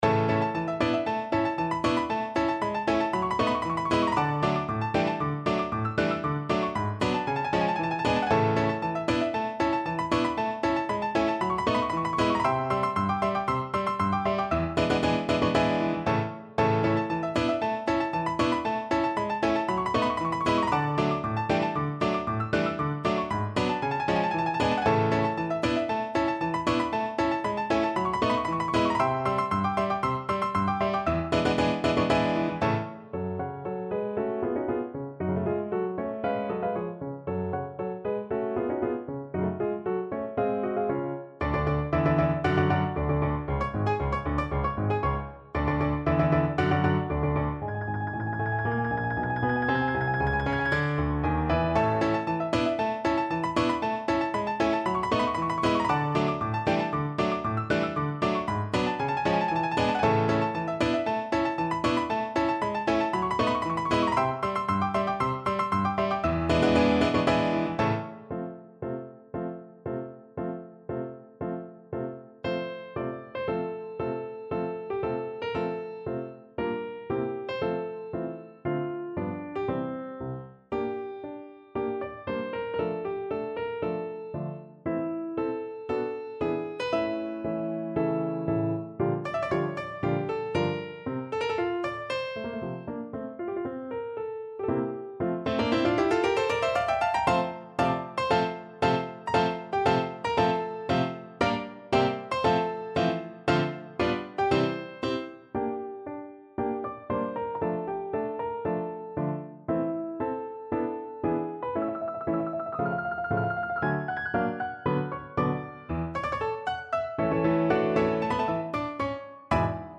No parts available for this pieces as it is for solo piano.
2/4 (View more 2/4 Music)
Piano  (View more Advanced Piano Music)
Classical (View more Classical Piano Music)